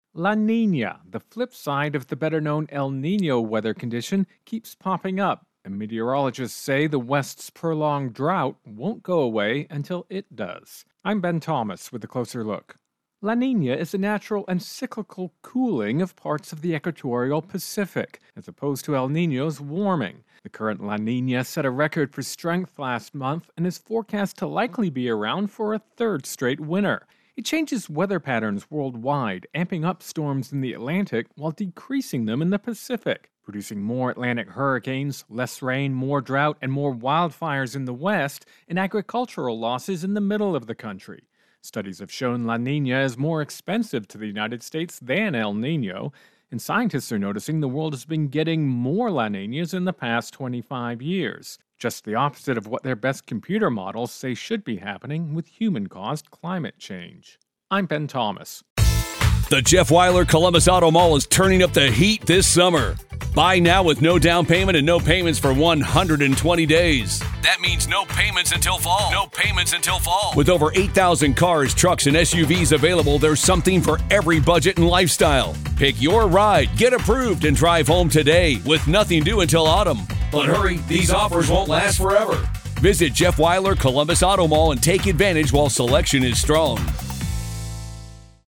Intro and voicer "Climate-La Nina"